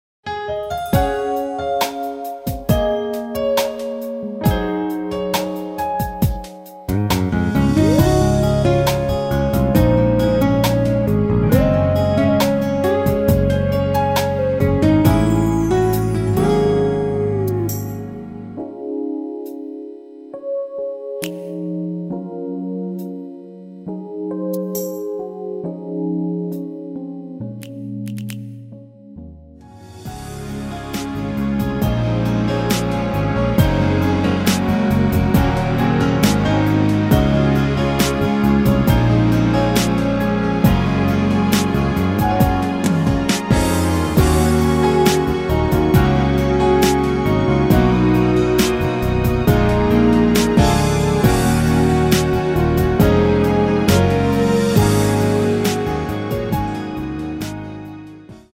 키 Db
원곡의 보컬 목소리를 MR에 약하게 넣어서 제작한 MR이며